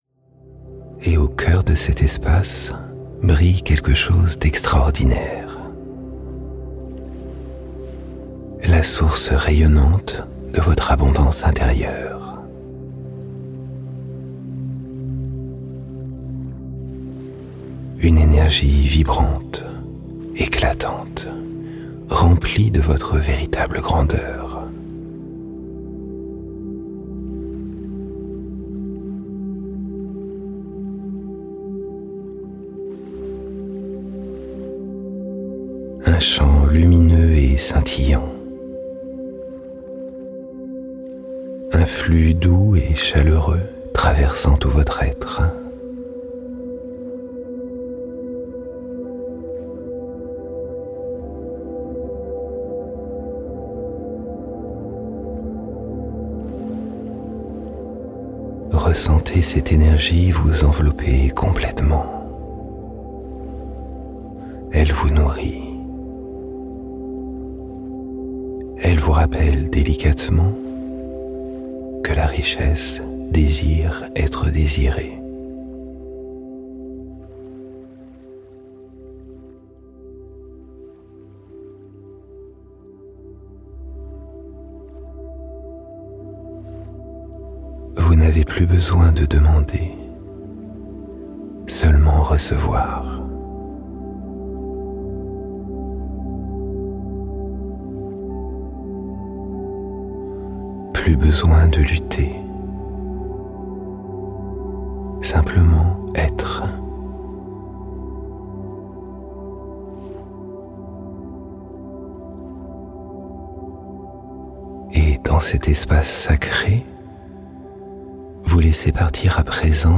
Une hypnose guidée puissante pour reprogrammer votre subconscient pendant le sommeil.